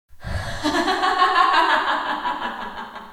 female evil laughter
evil female laugh laughter sound effect free sound royalty free Funny